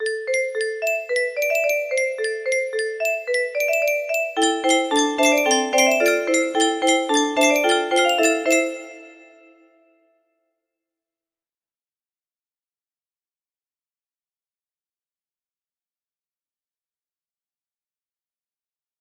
amnesia music box melody
Full range 60